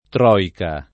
troika [ tr 0 ika ] o troica [id.] s. f.